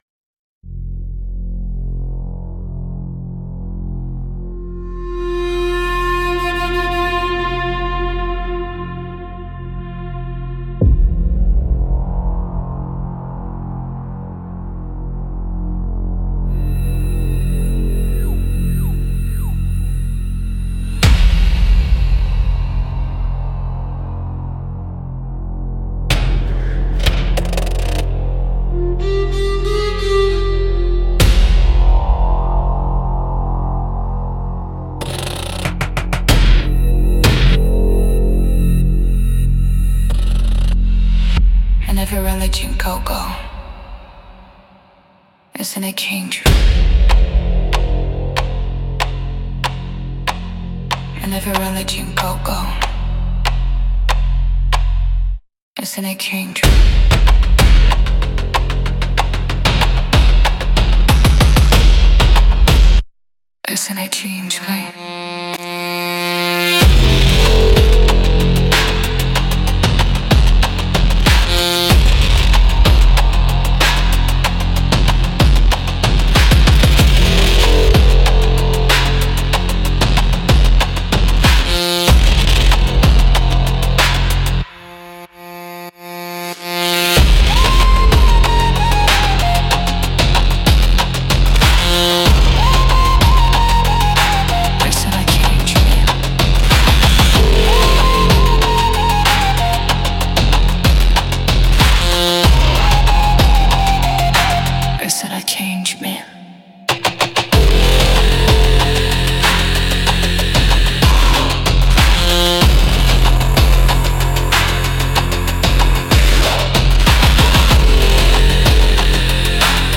Instrumental - Heart of the Drum -3.08